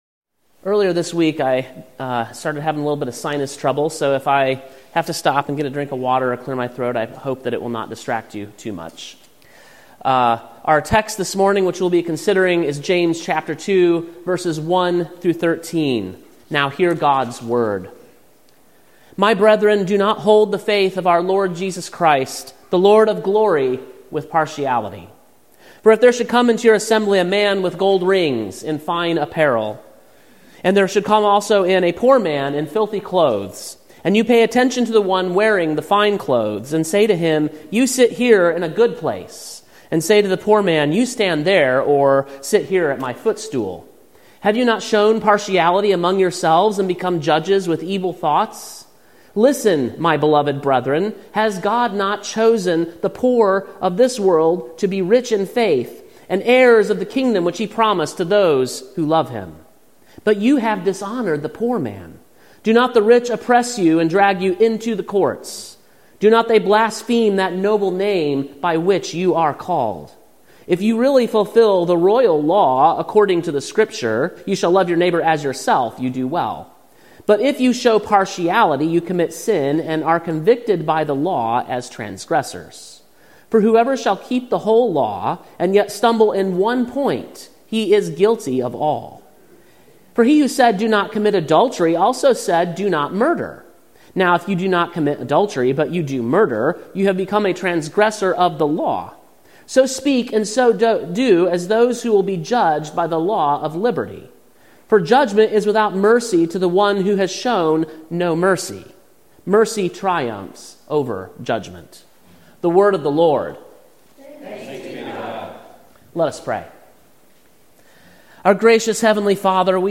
Sermon preached on August 25, 2024, at King’s Cross Reformed, Columbia, TN.